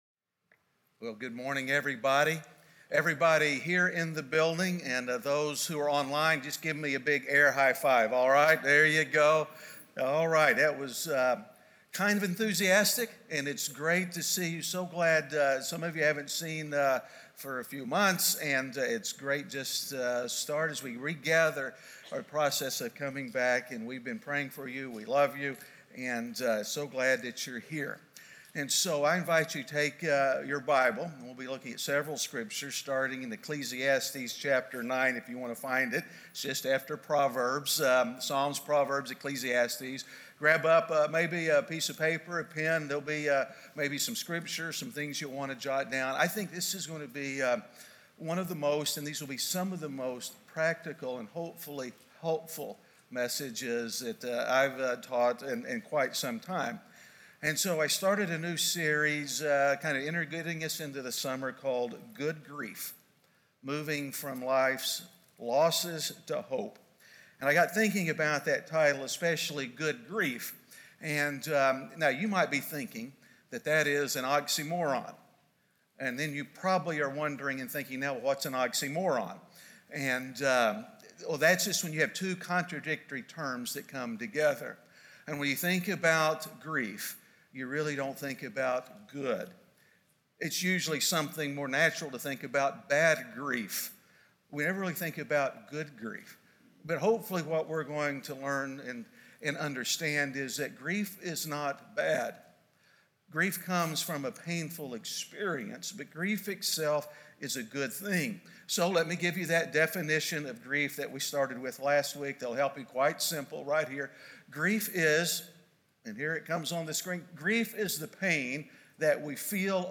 A message from the series "Good Grief." How we deal with the minor instances of grief will in large part determine how we will deal with the major griefs we experience.